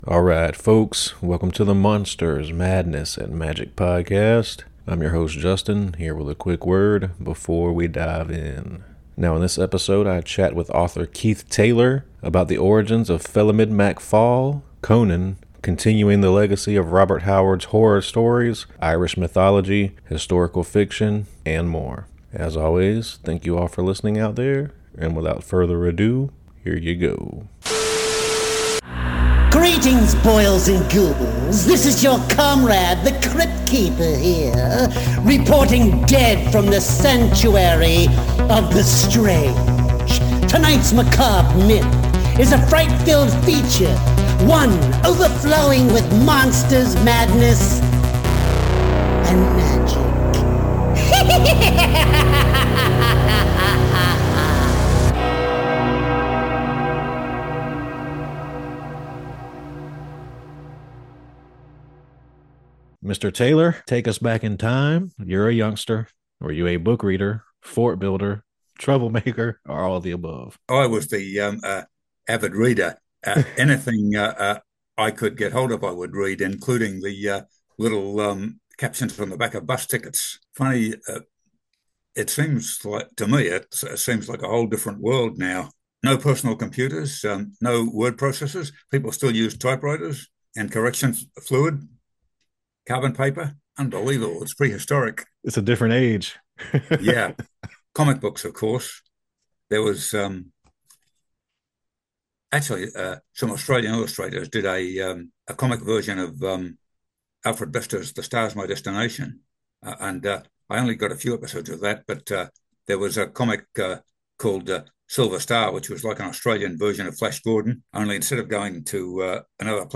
EP#302: The Fate of Felimid Mac Fal - An Interview